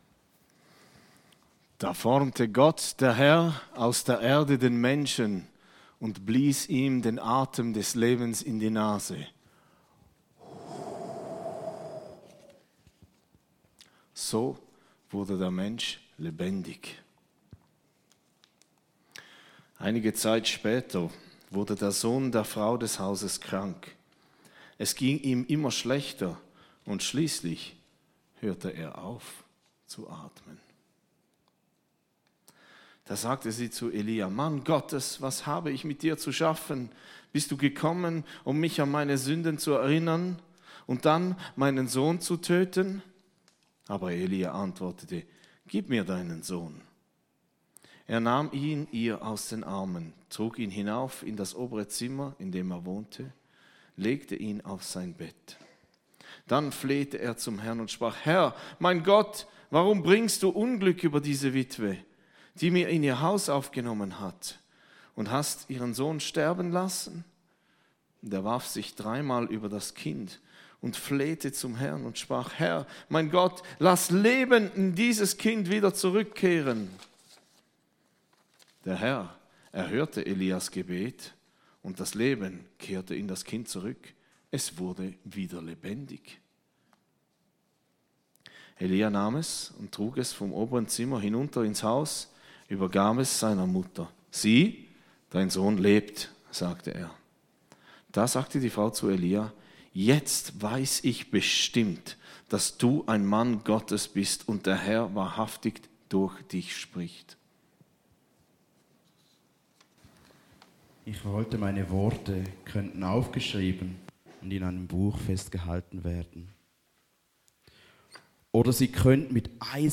Schriftenlesung - Auferstehung Jesus ~ Predigten D13 Podcast